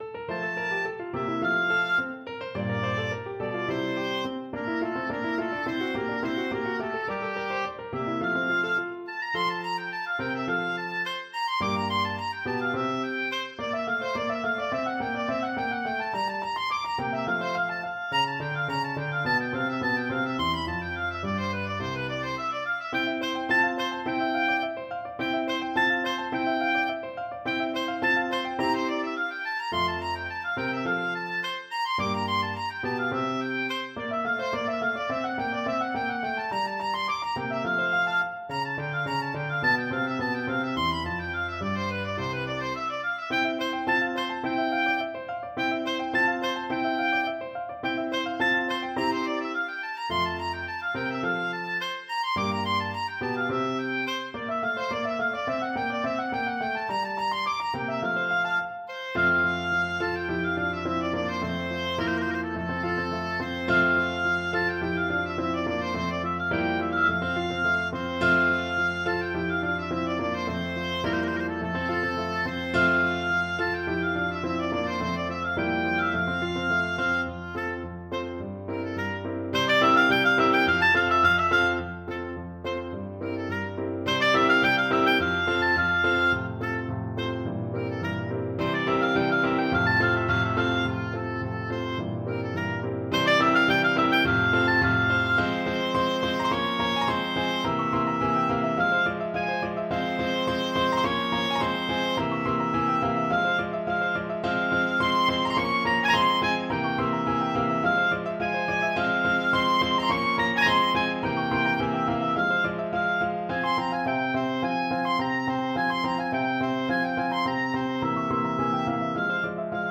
F major (Sounding Pitch) (View more F major Music for Oboe )
2/4 (View more 2/4 Music)
Presto = 106 (View more music marked Presto)
Classical (View more Classical Oboe Music)